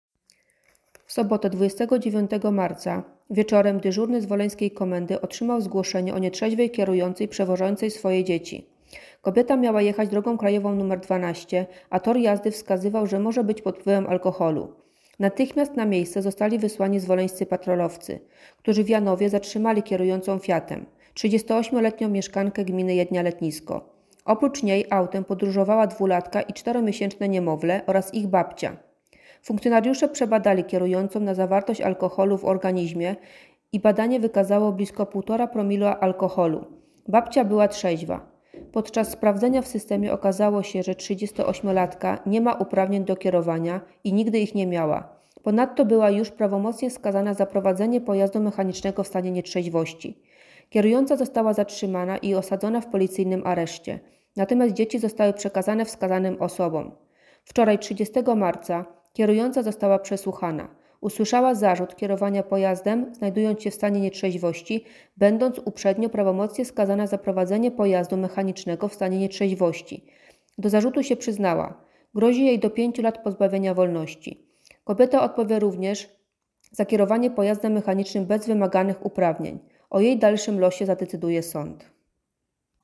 Nagranie audio Pijana matka wiozła swoje dzieci - wypowiedź